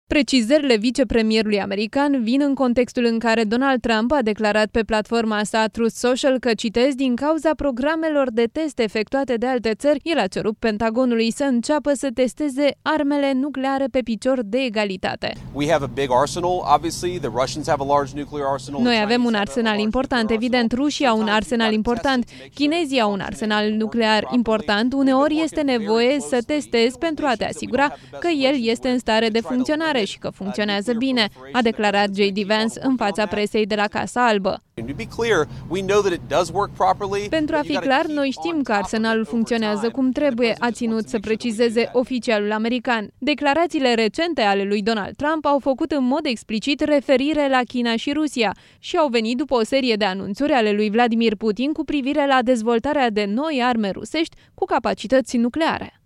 „Noi avem un arsenal important, evident. Rușii au un arsenal important. Chinezii au un arsenal nuclear important. Uneori, este nevoie să testezi pentru a vă asigura că el este în stare de funcționare și că funcționează bine”, a declarat JD Vance în fața presei la Casa Albă.